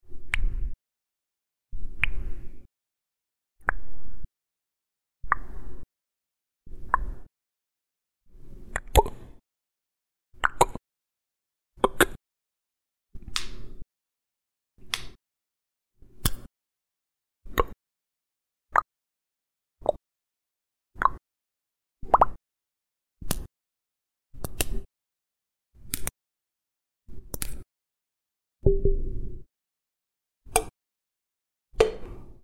Minimal UI Pack Pops Clicks Ticks